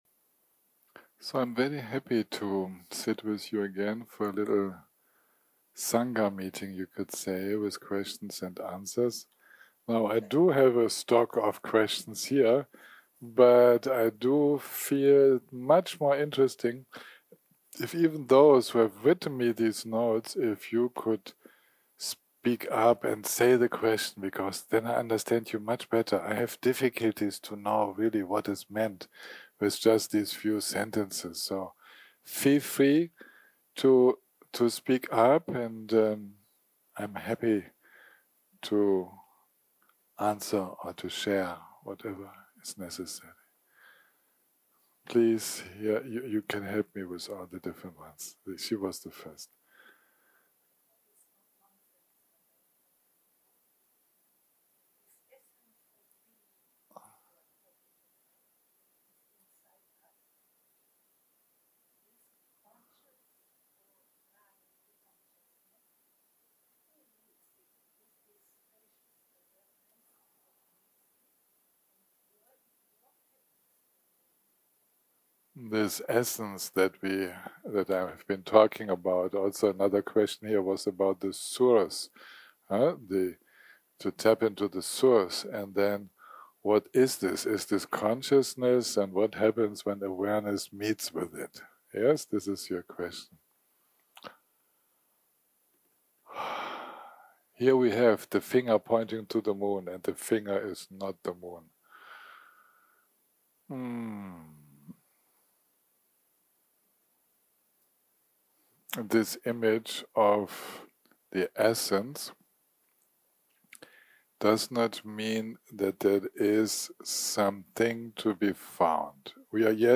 יום 3 - הקלטה 12 - אחהצ - שאלות ותשובות
סוג ההקלטה: שאלות ותשובות